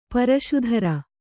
♪ paraśu dhara